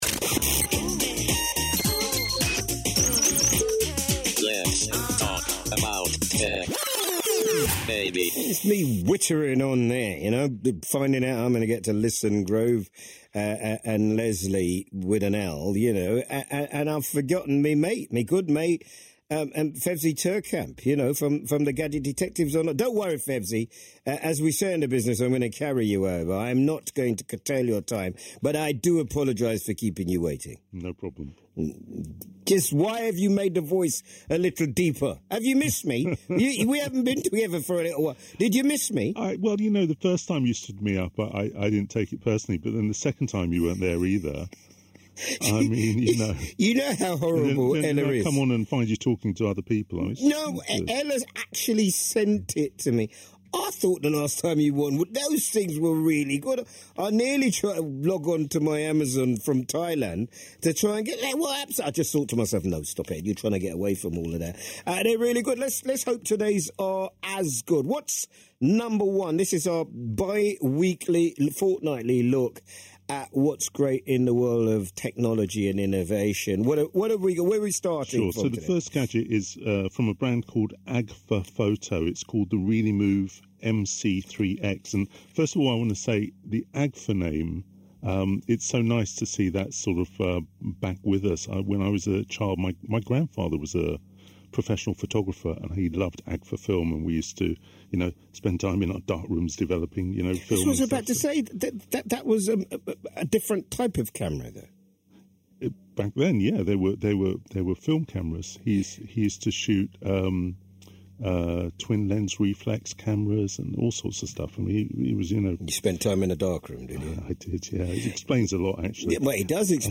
26th June 2025 - Tech Reviews on BBC Radio London